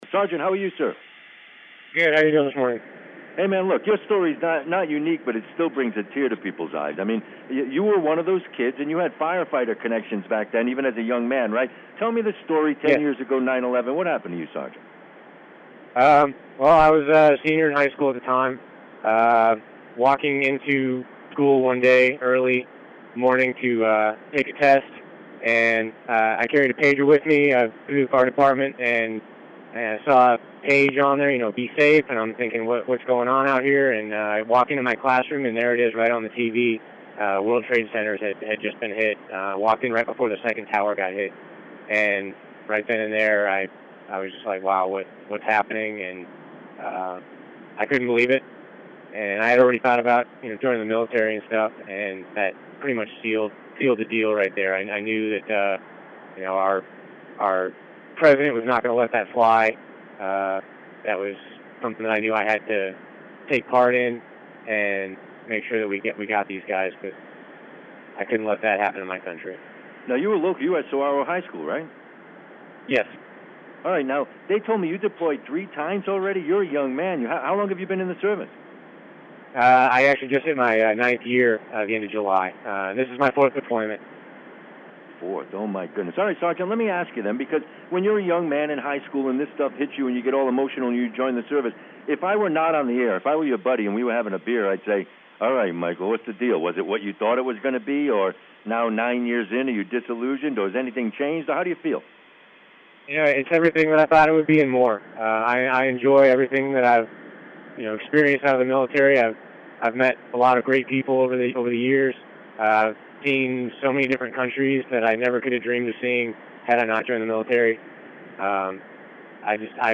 talks to a KVOI-AM reporter in Tucson about how the terrorist attacks of Sept. 11, 2001 influenced his decision to join the military. 911TenAnniv